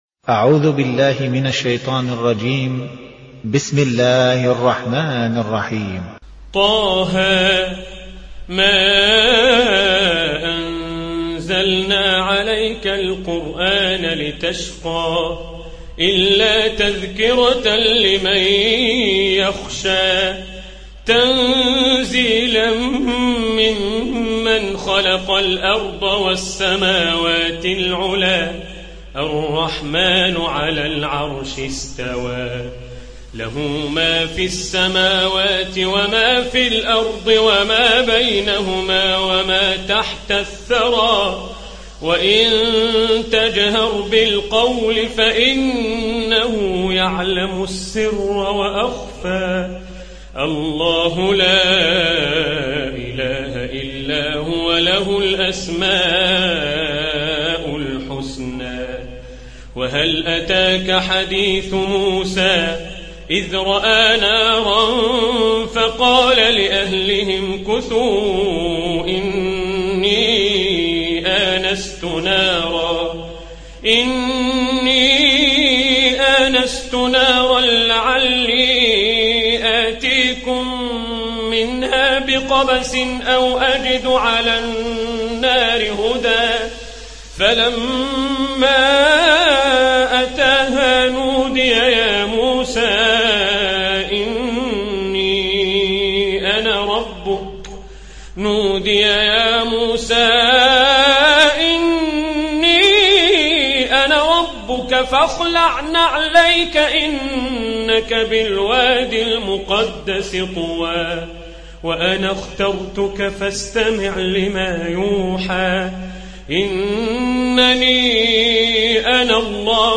Riwayat Hafs an Assim